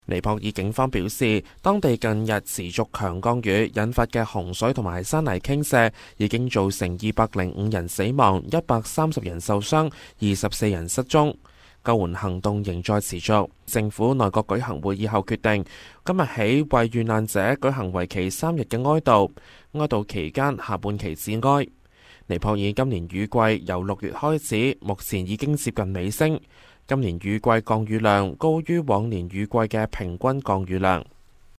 news_clip_20758.mp3